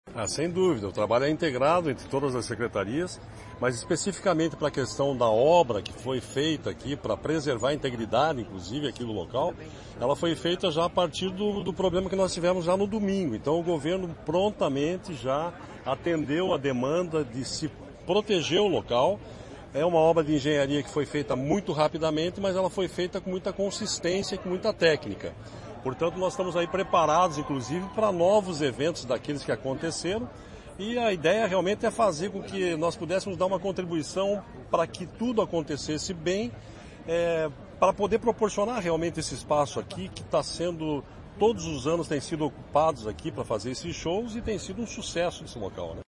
Sonora do diretor-presidente do IAT, Everton Souza, sobre o primeiro fim de semana de shows do Verão Maior Paraná